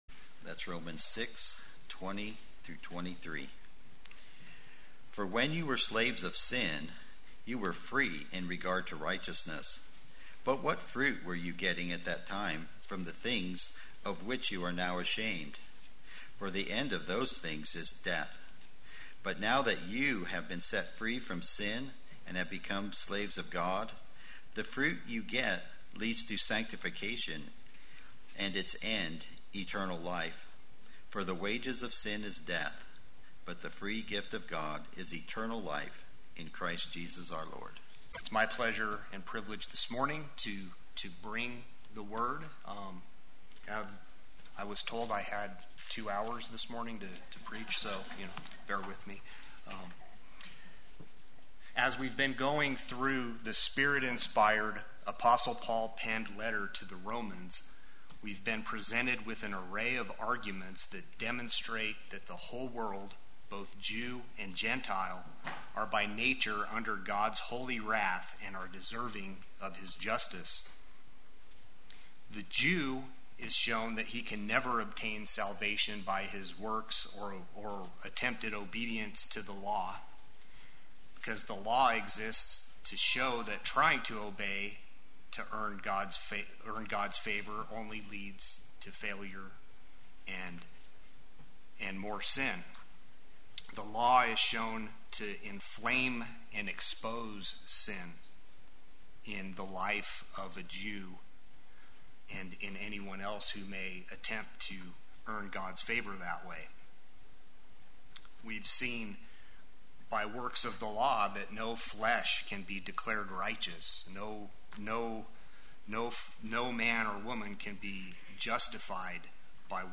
Play Sermon Get HCF Teaching Automatically.
Enslaved to God Sunday Worship